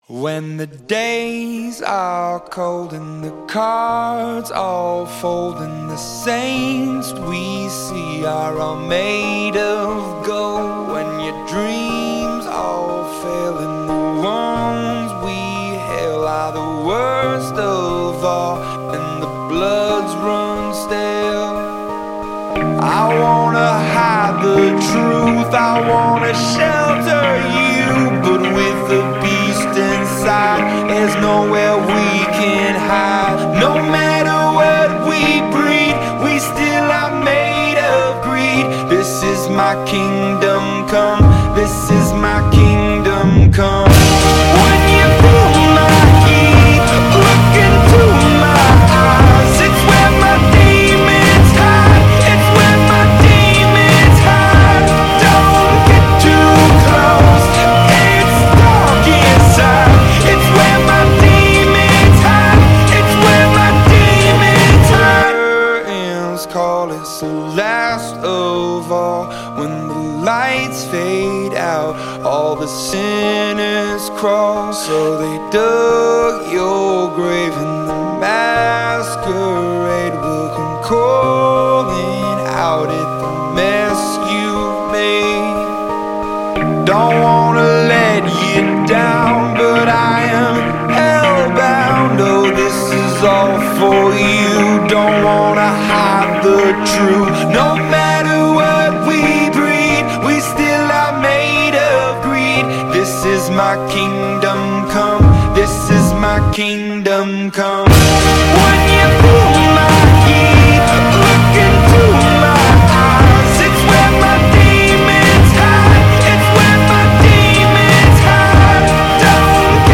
Rock 2010er